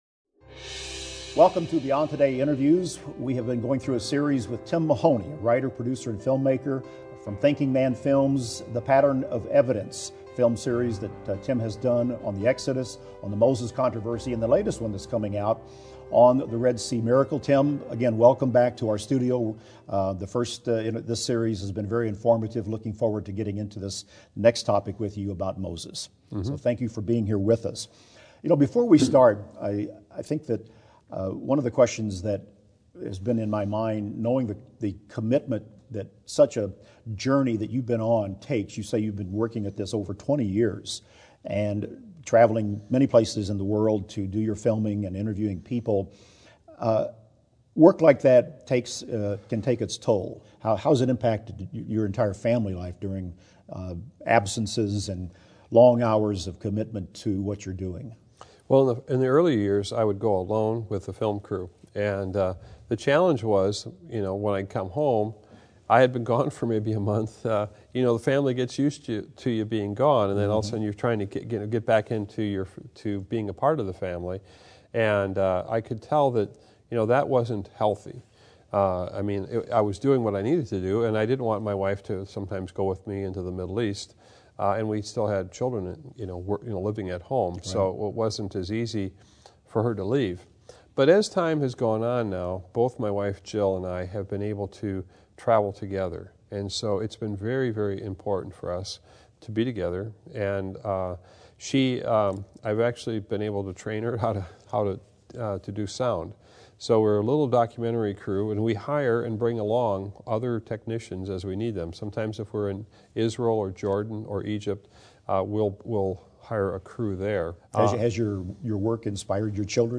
Beyond Today Interview: Biblical Evidence